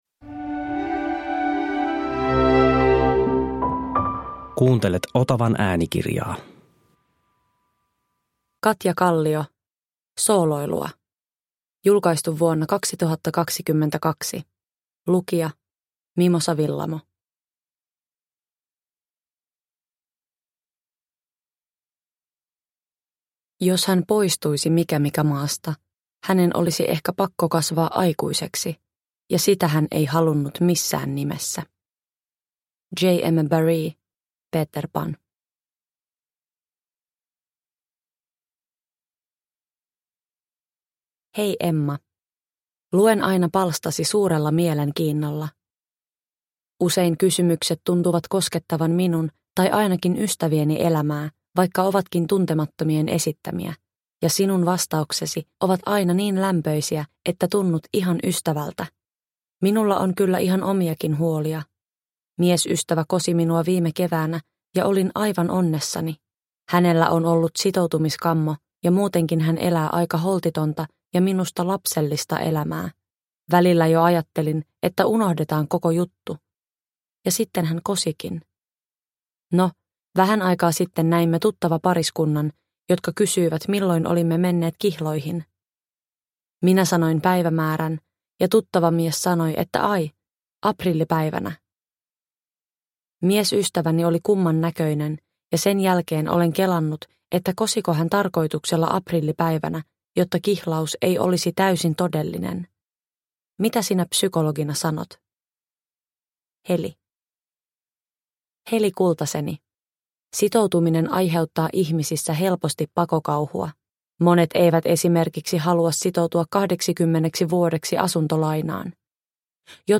Sooloilua – Ljudbok – Laddas ner
Uppläsare: Mimosa Willamo